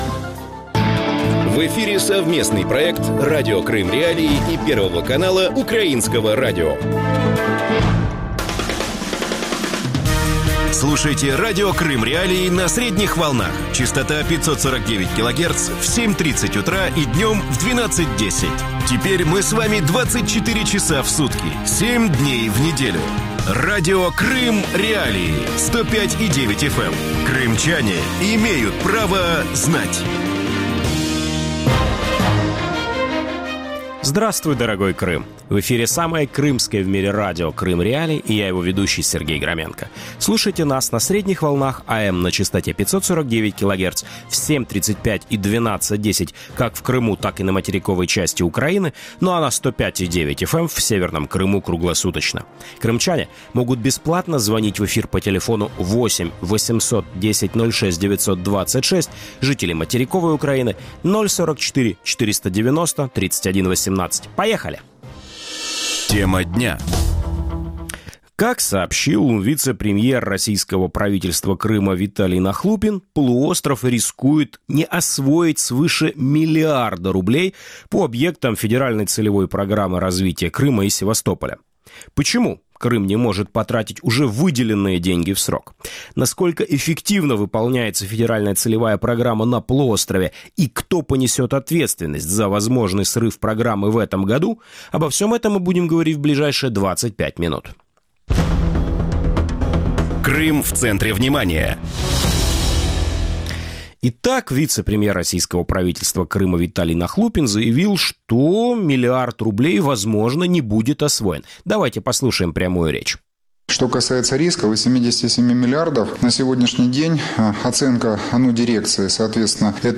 российский политолог
российский общественный активист
украинский аналитик